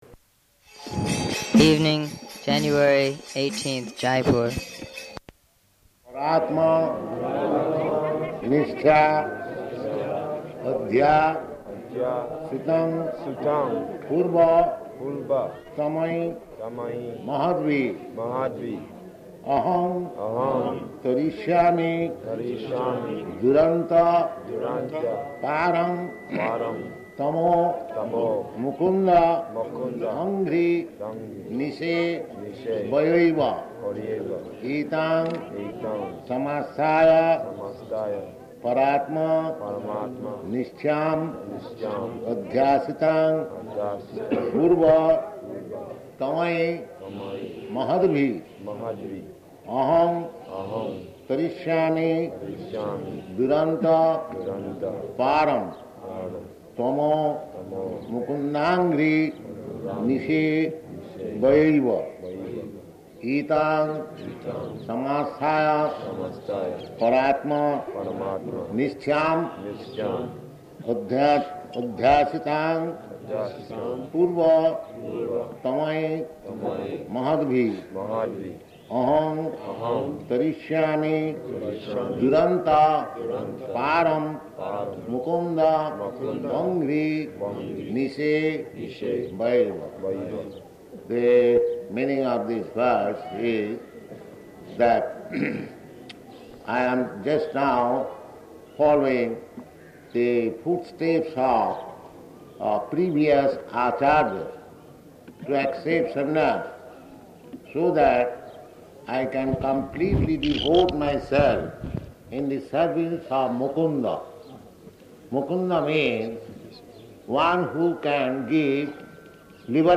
Location: Jaipur